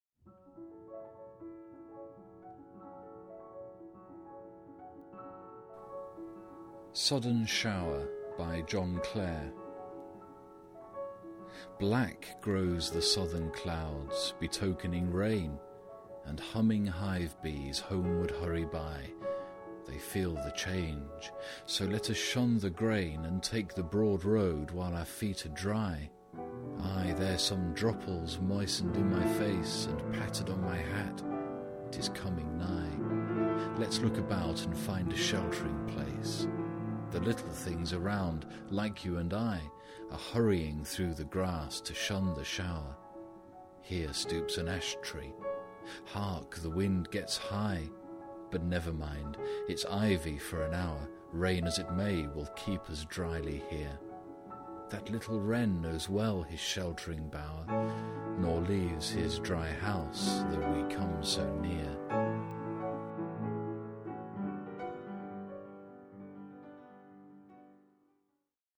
Music from Miroirs by Maurice Ravel.